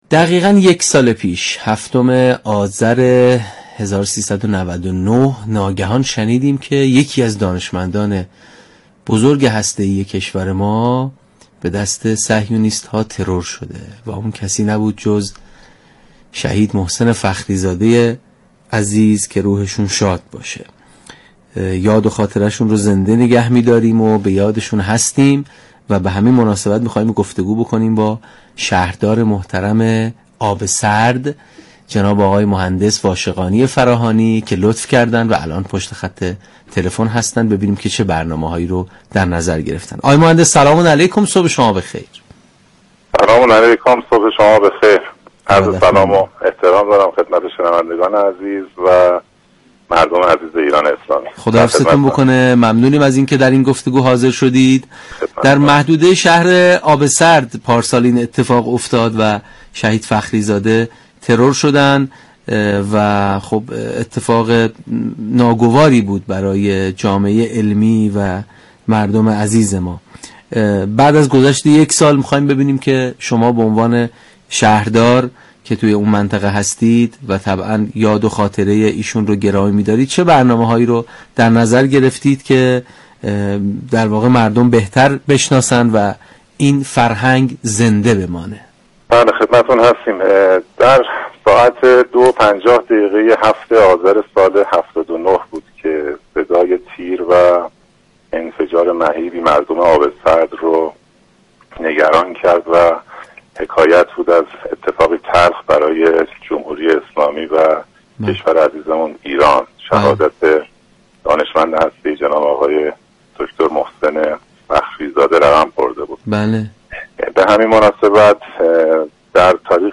به گزارش پایگاه اطلاع رسانی رادیو قرآن ؛ محسن واشقانی فراهانی شهردار آبسرد در گفتگو با برنامه تسنیم 7 آذر 1400 گفت : به مناسبت سالروز شهادت شهید محسن فخری‌زاده، مراسم رونمایی از اِلِمان پدر برنامه اتمی و حافظ بزرگترین اسرار هسته‌ای ایران در شهر آبسرد، محل شهادت شهید فخری‌زاده با حضور خانواده این شهید والا مقام و جمعی از مقامات كشوری و لشكری برگزار می‌شود.